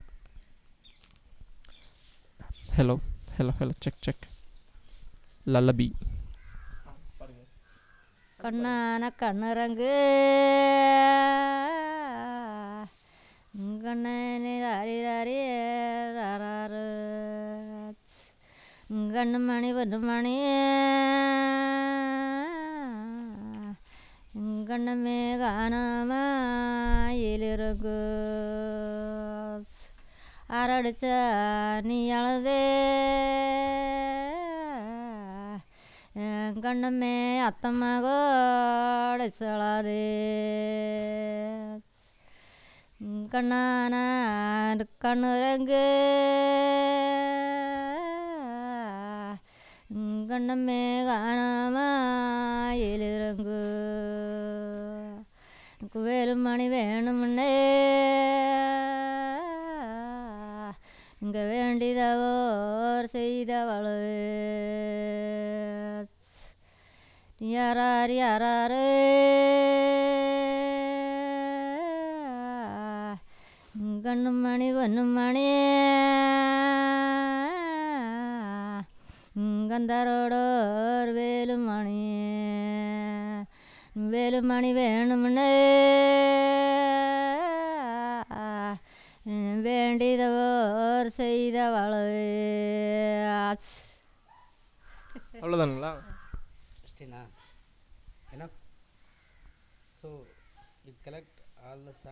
Performance of Lullaby